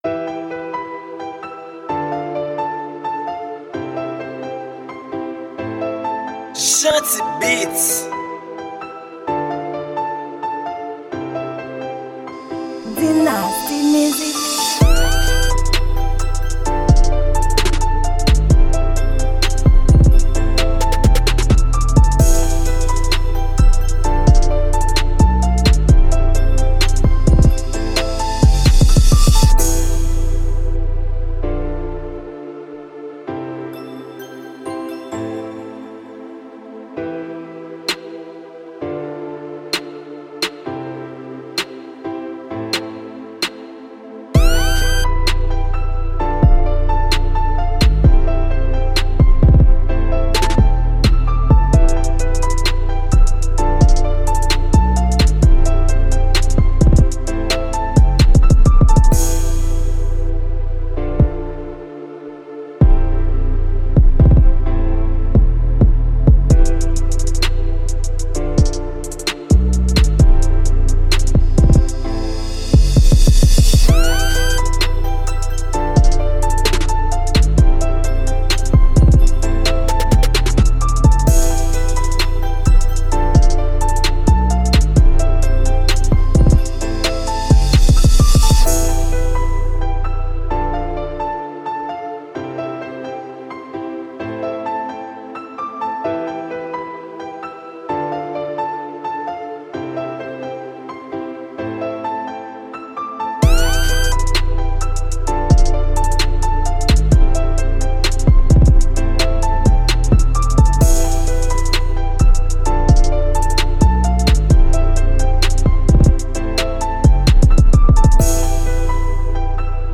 Genre: Beat